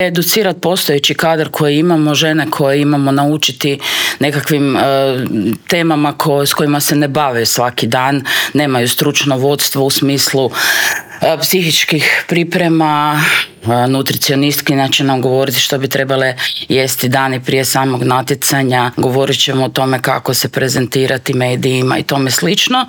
U intervjuu Media servisa o tome su pričale naše gošće